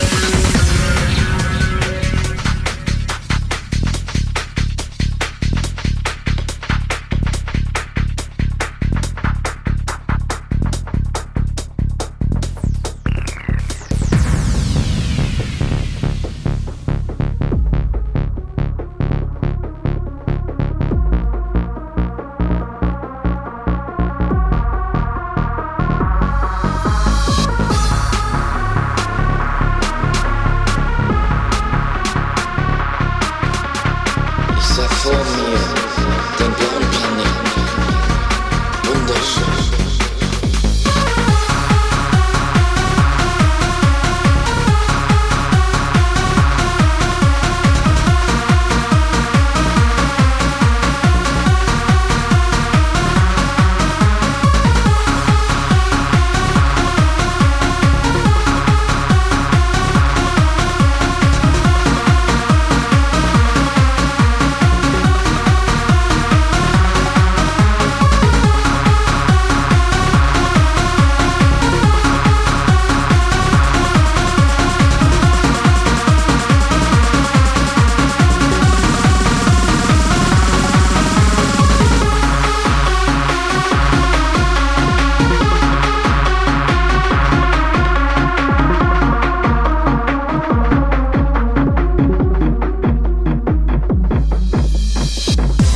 Genre: Club.